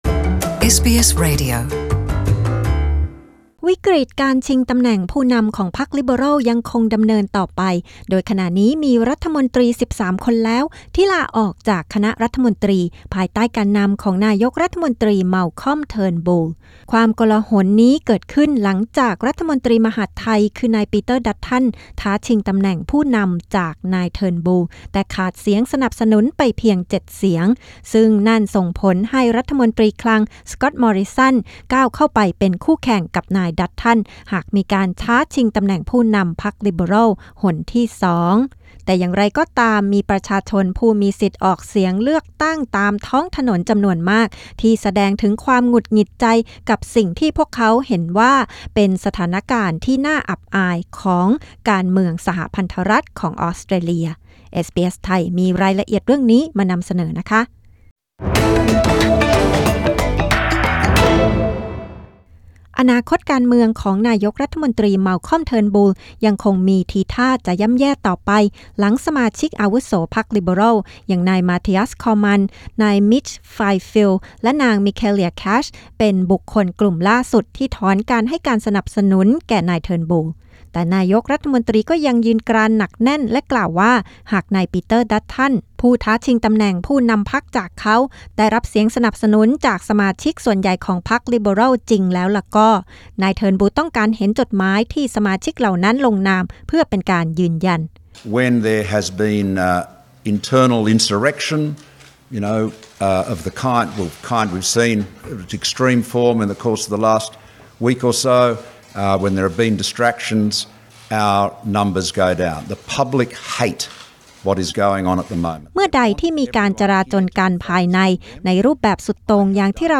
ผู้มีสิทธิ์เลือกตั้งตามท้องถนนหลายต่อหลายคนแสดงความไม่พอใจต่อสิ่งที่พวกเขามองว่าเป็นความเละเทะของการเมืองออสเตรเลียในระดับสหพันธรัฐ